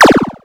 ihob/Assets/Extensions/RetroGamesSoundFX/Shoot/Shoot18.wav
Shoot18.wav